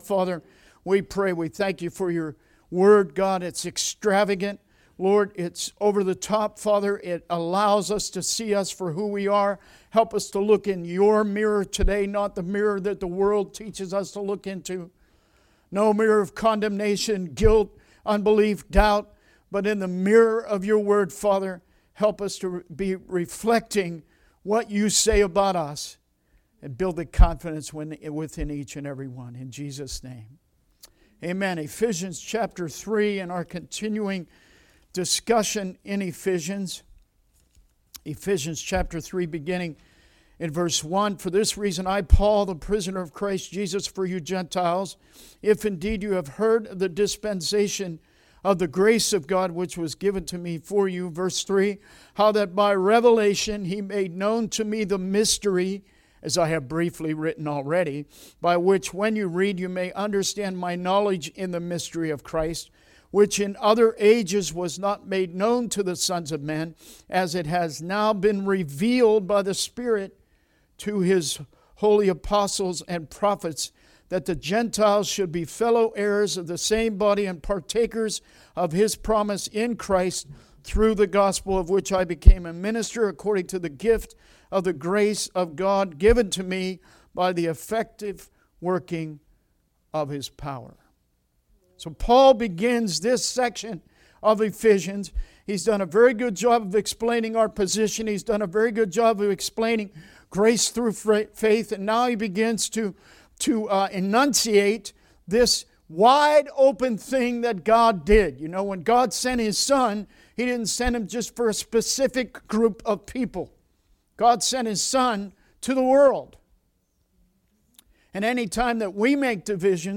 Sermon messages available online.
Service Type: Sunday Teaching